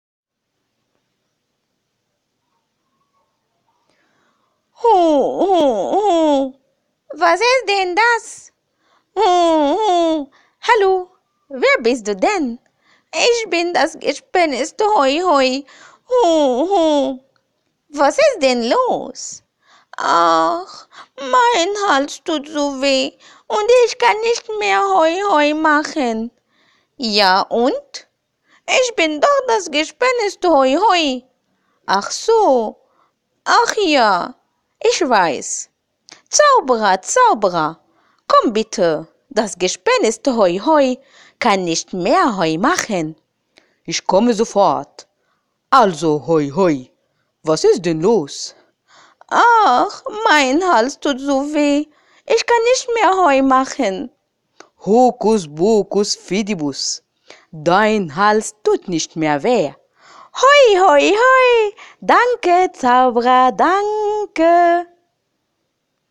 动画角色